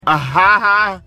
Play, download and share ah ha ha original sound button!!!!
ah-ha-ha.mp3